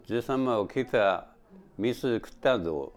Type: Statement
Final intonation: Falling
Location: Showamura/昭和村
Sex: Male